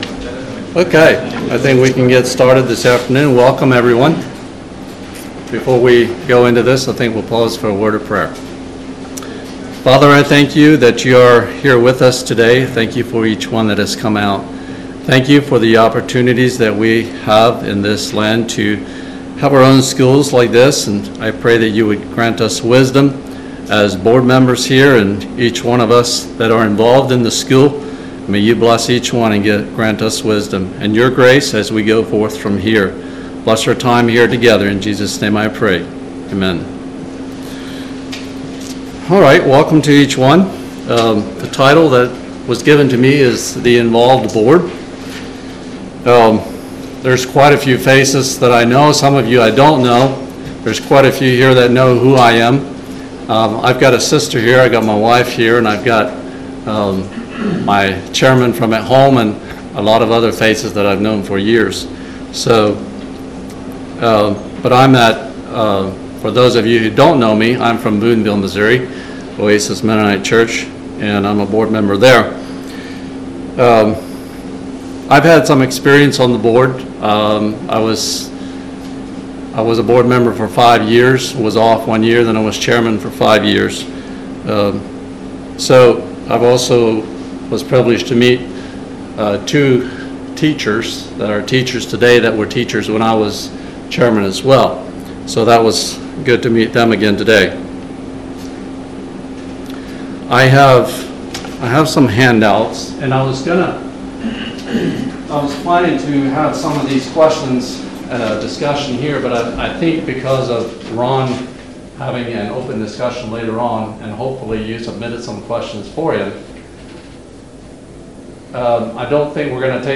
2025 Midwest Teachers Week 2025 Recordings The Involved Board Audio 00:00